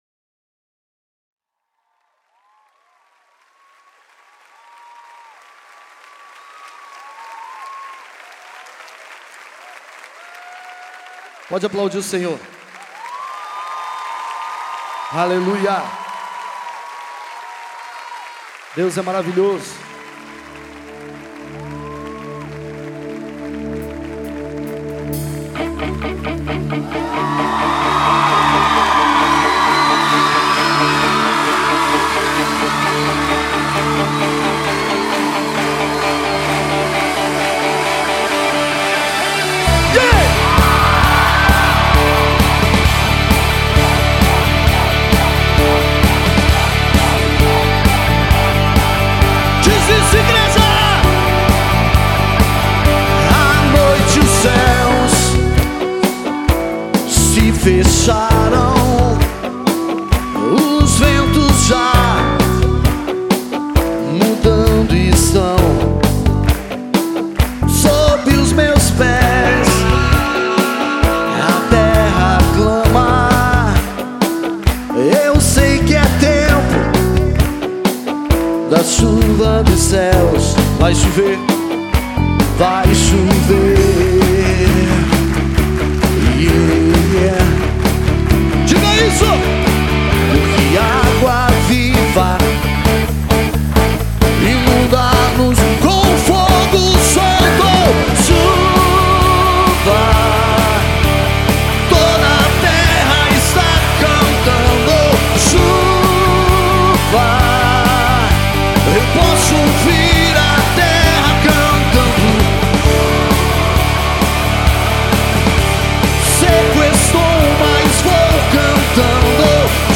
gravado ao vivo
com mais de 6 mil pessoas presentes no evento.